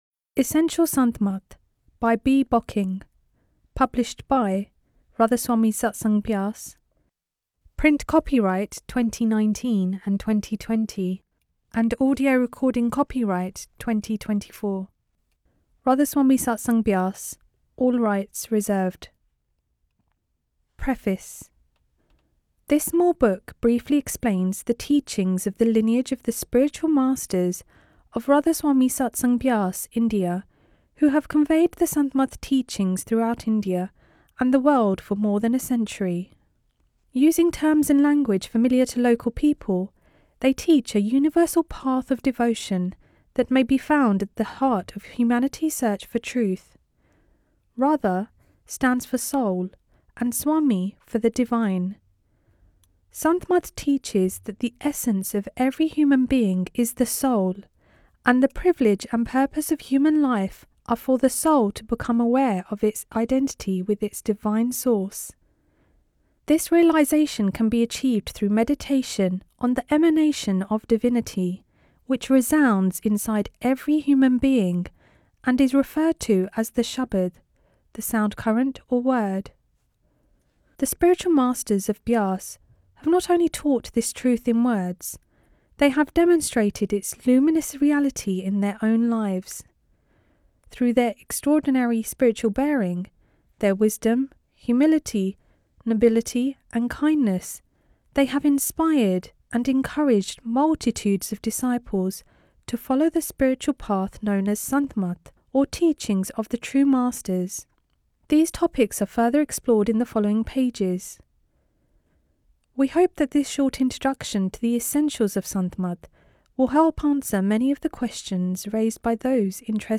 Essential Sant Mat - RSSB Audio Books